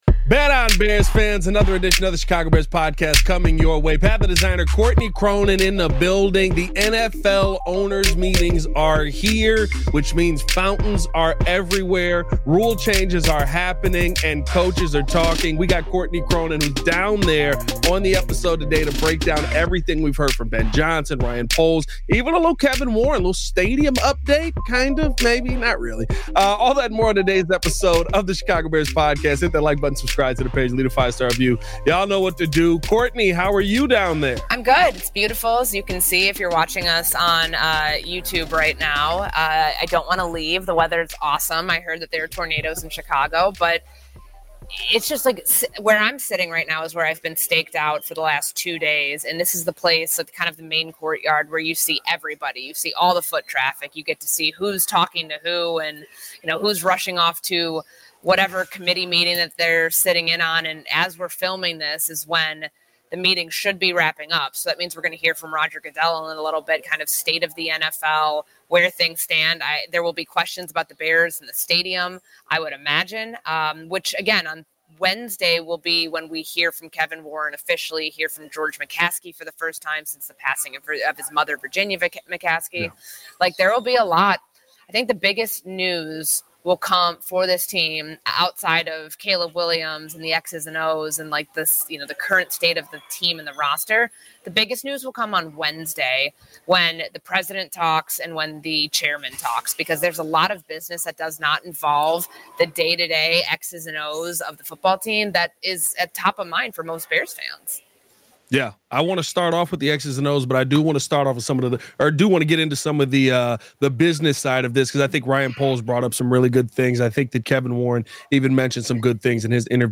Live from NFL Owners Meetings in Florida